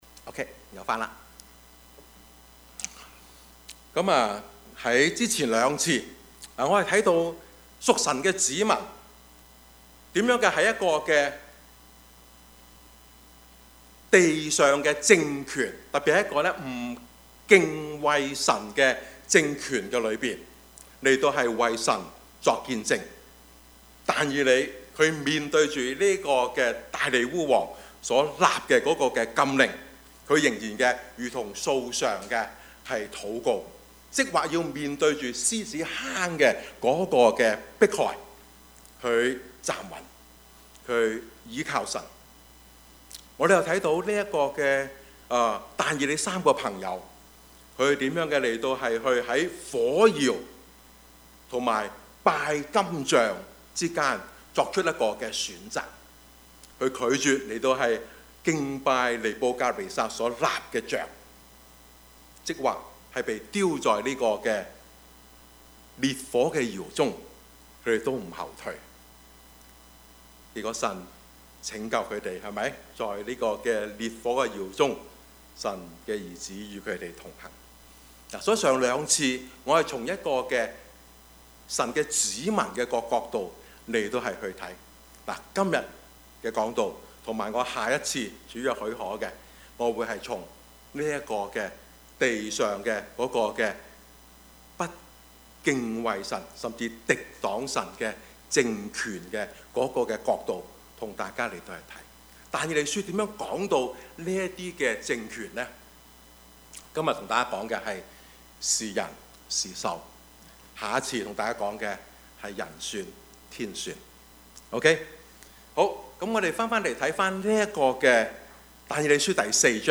2019 主日崇拜